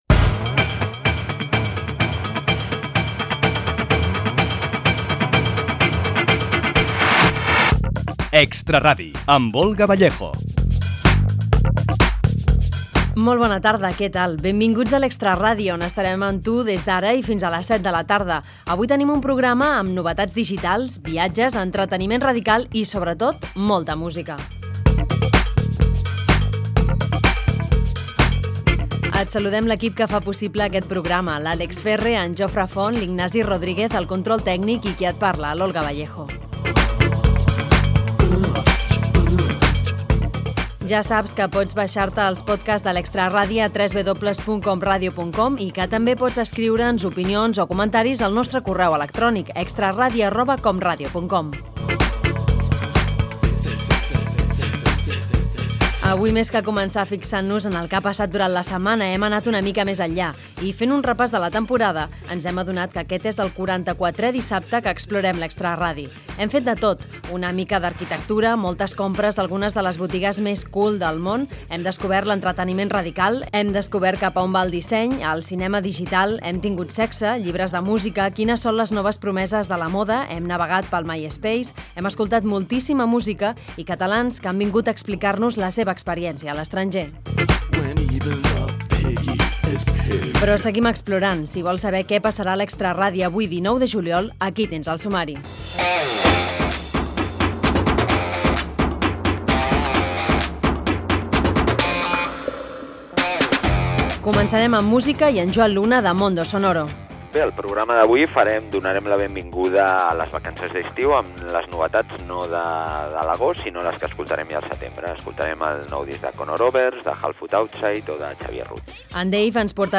Careta del programa, presentació, crèdits i i sumari dels continguts
Entreteniment